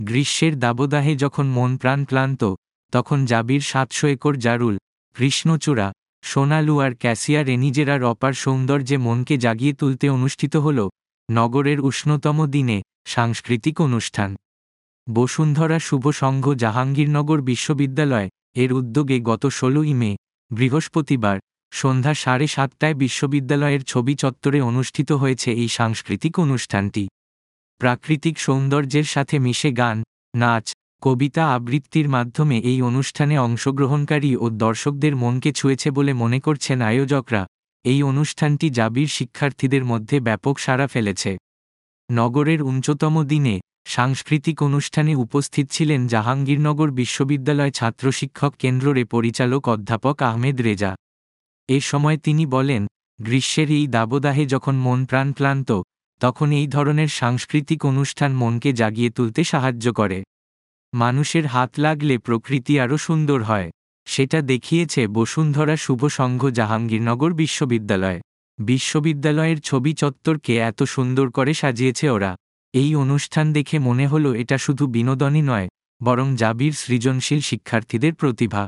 বসুন্ধরা শুভ সংঘ জাহাঙ্গীরনগর বিশ্ববিদ্যালয় -এর উদ্যোগে গত ১৬ই মে (বৃহস্পতিবার) সন্ধ্যা সাড়ে সাতটায় বিশ্ববিদ্যালয়ের ছবি চত্বরে অনুষ্ঠিত হয়েছে এই সাংস্কৃতিক অনুষ্ঠানটি।